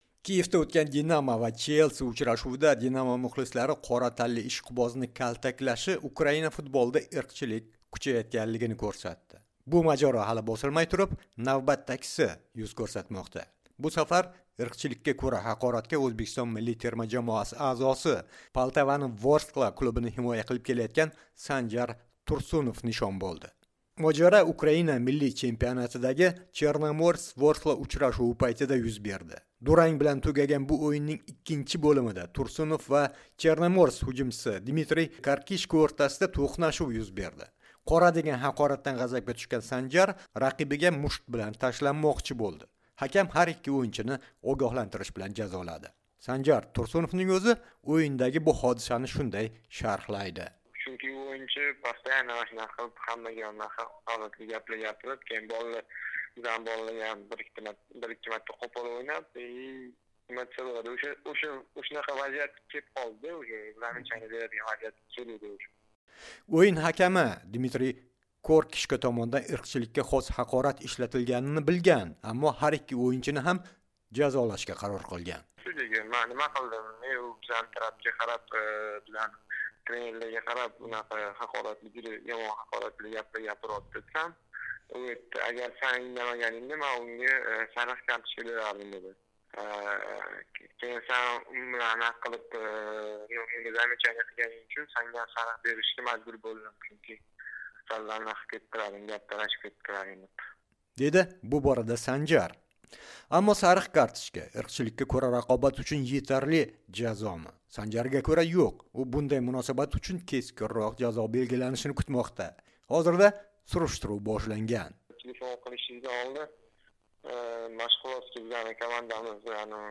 Sanjar Tursunov bilan suhbat